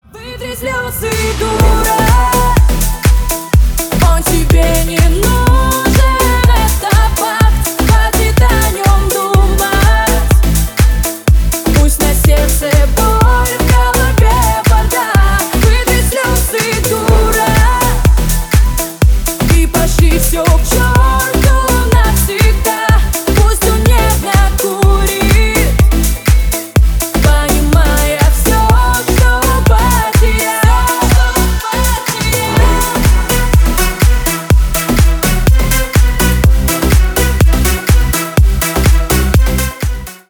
бесплатный рингтон в виде самого яркого фрагмента из песни
Ремикс # Поп Музыка